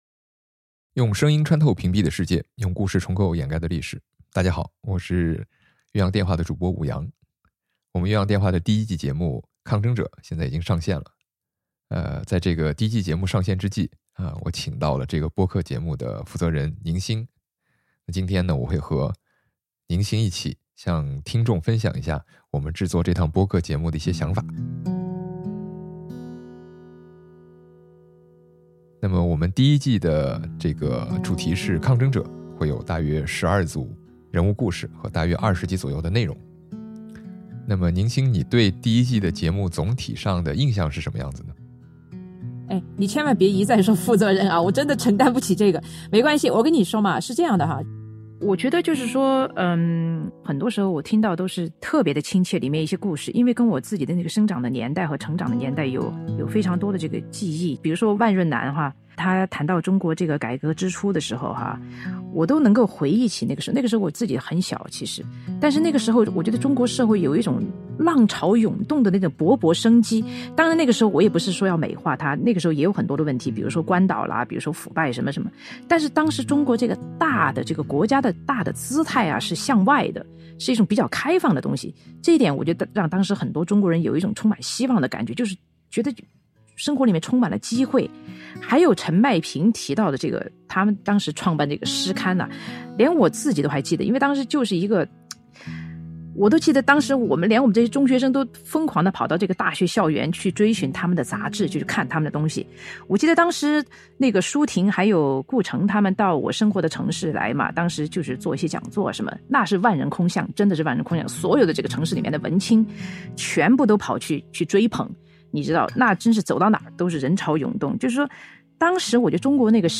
越洋电话：“抗争者” - 开播特别对话，声音与故事